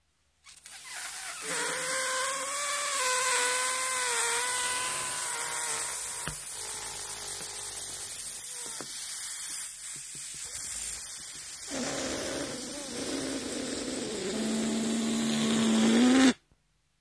CURRYS BALLOON DEFLATING HAS BEEN sound effects free download